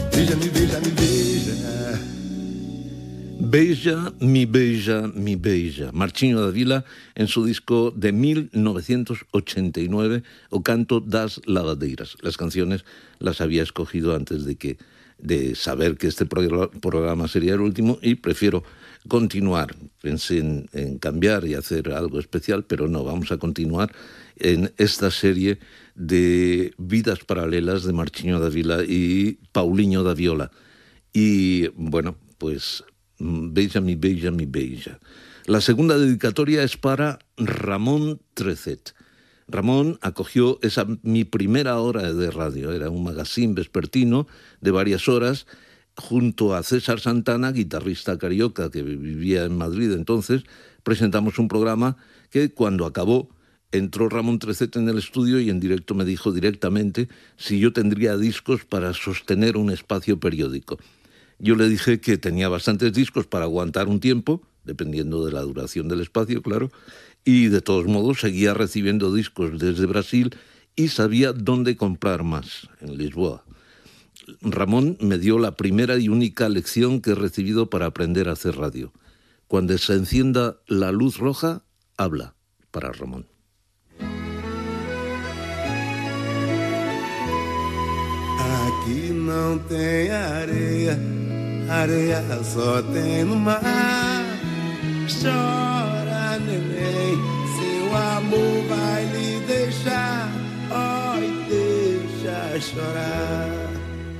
Fragments del darrer programa després de 42 anys en antena.
Musical